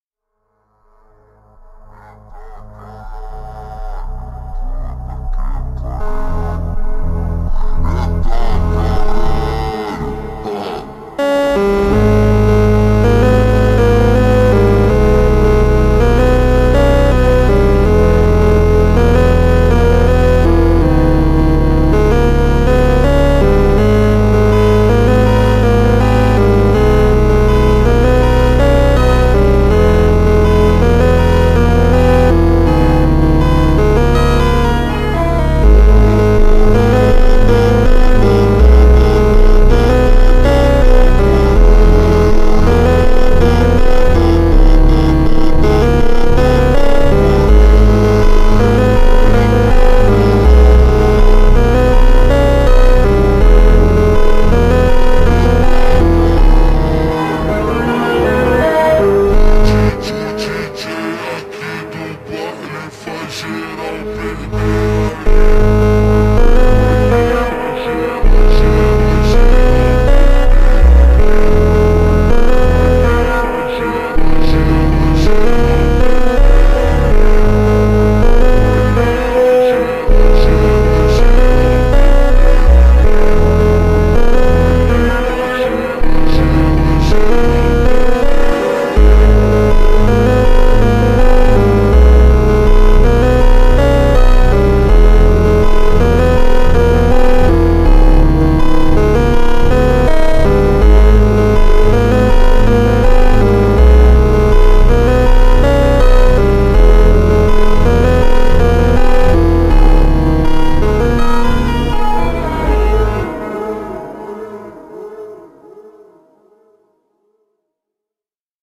landing-phonk.mp3